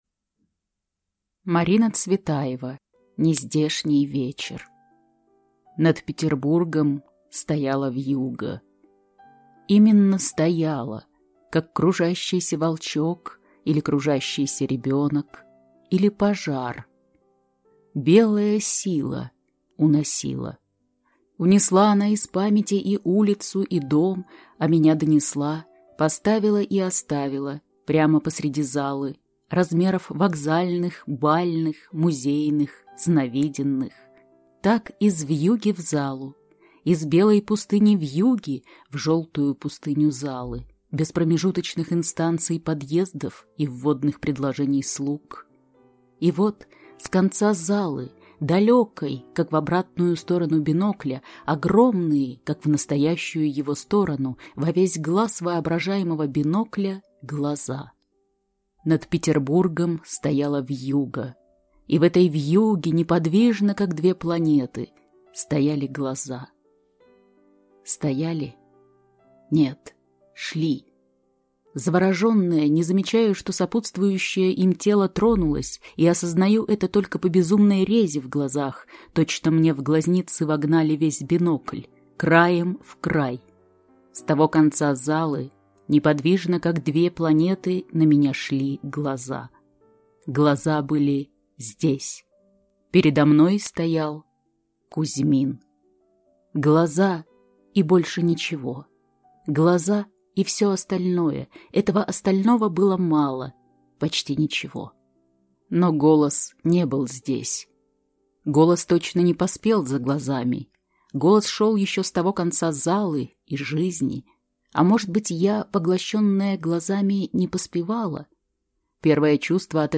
Аудиокнига Нездешний вечер | Библиотека аудиокниг